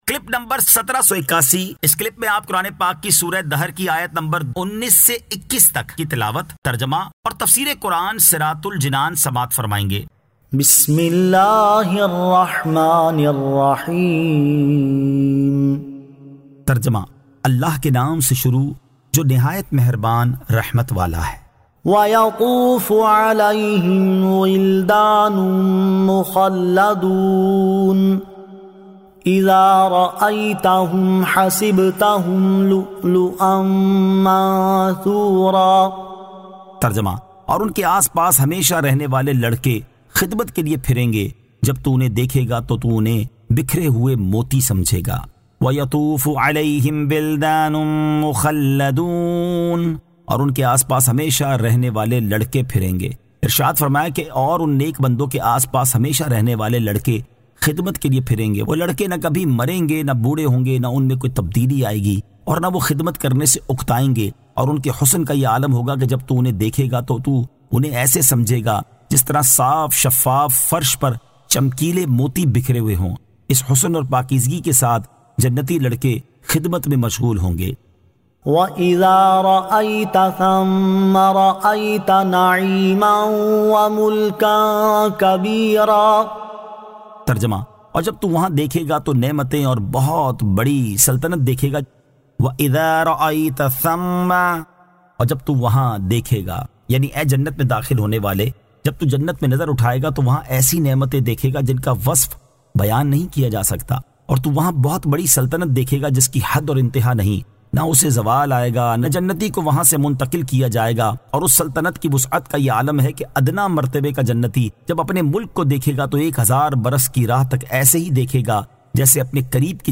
Surah Ad-Dahr 19 To 21 Tilawat , Tarjama , Tafseer